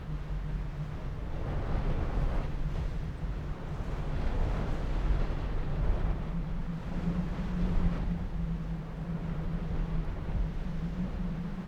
Wind Moaning
Category: Sound FX   Right: Personal
Tags: Forest Wind Waves